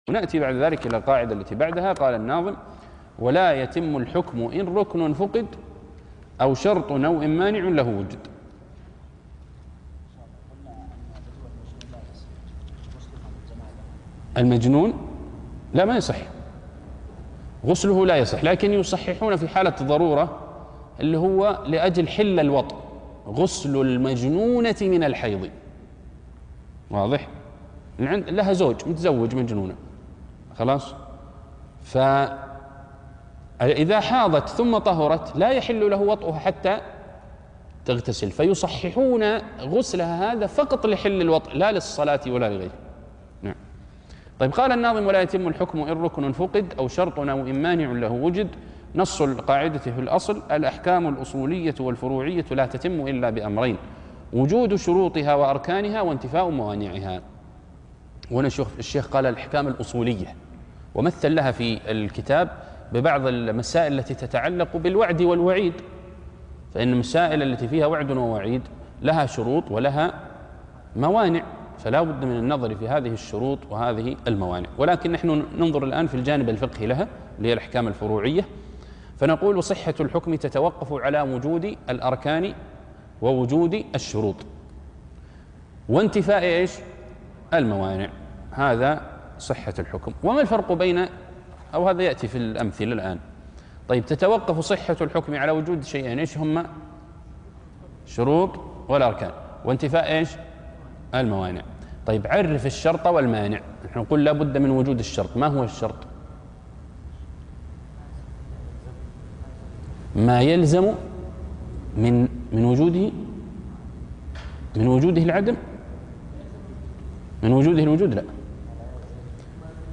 عنوان المادة الدرس ( 10) ( المساعد لفهم نظم القواعد)